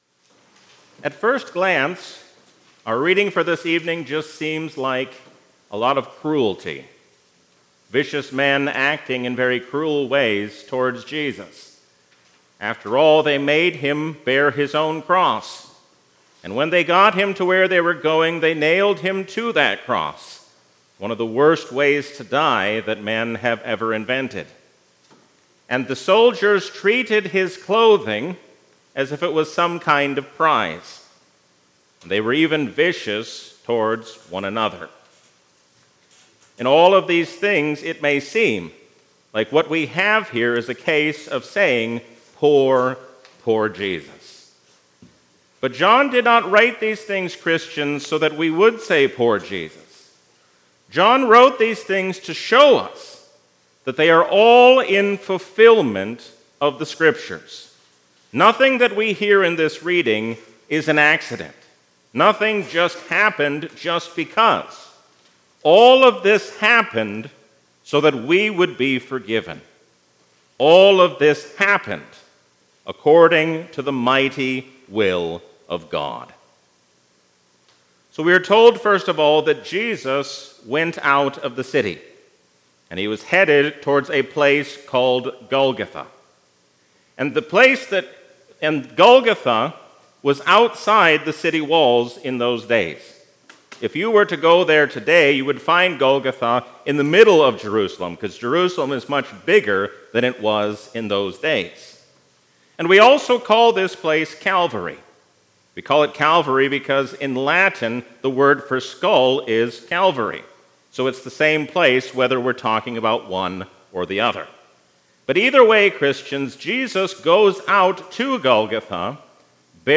A sermon from the season "Lent 2024." All those who know the truth listen to the voice of Jesus, because He is the Truth who has come to set us free.